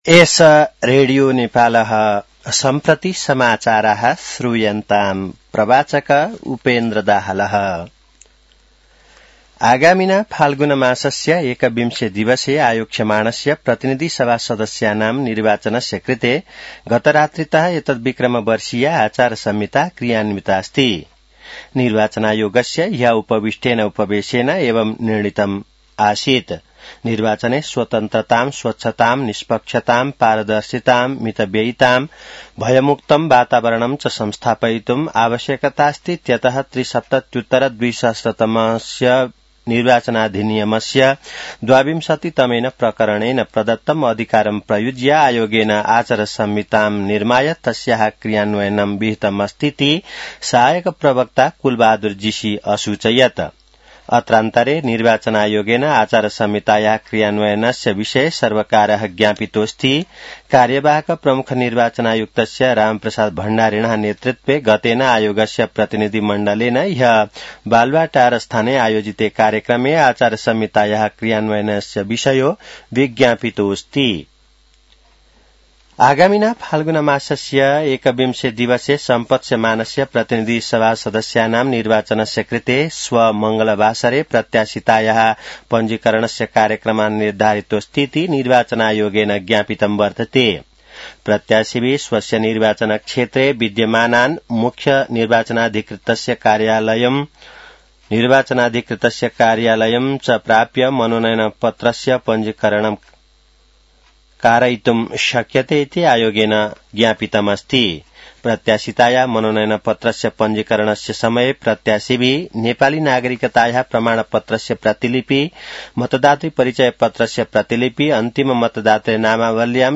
An online outlet of Nepal's national radio broadcaster
संस्कृत समाचार : ५ माघ , २०८२